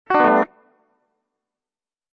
Descarga de Sonidos mp3 Gratis: guitarra 18.